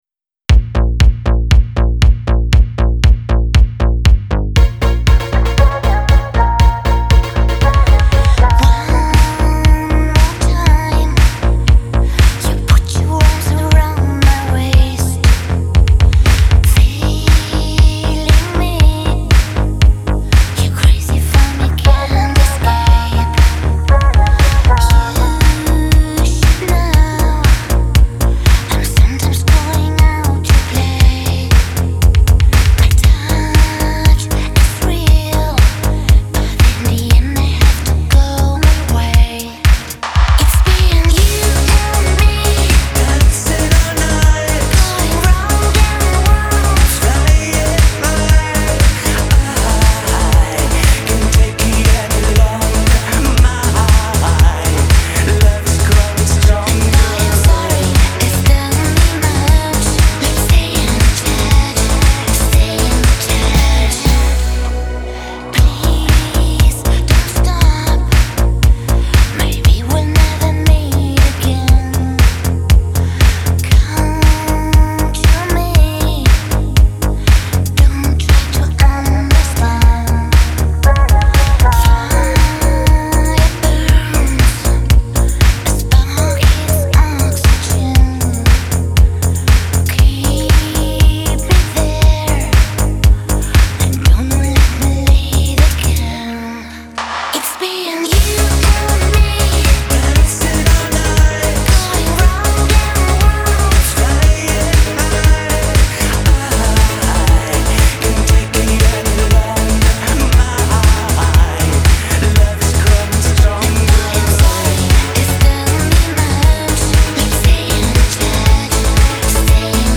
• Жанр: Корейские песни